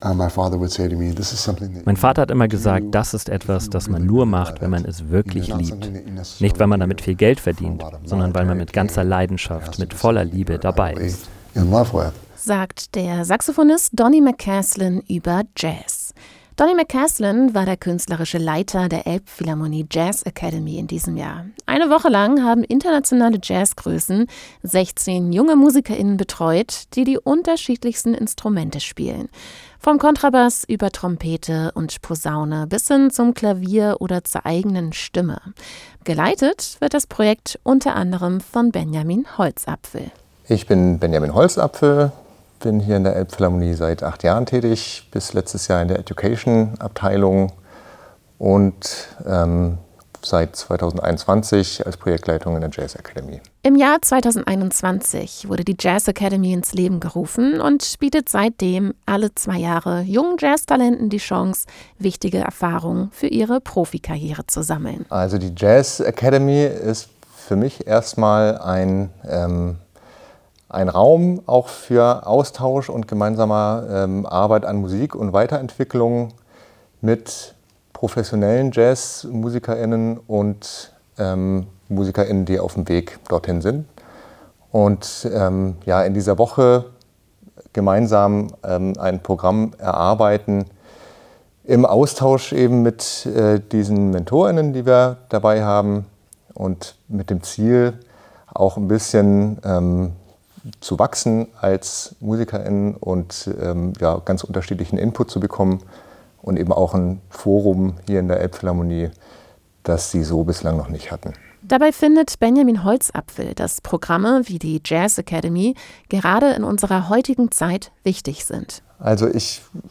Interviews und Eindrücke von der hochkarätigen Jazz-Masterclass in der Elbphilharmonie – ein Podcast des Hamburger Radiosenders ByteFM.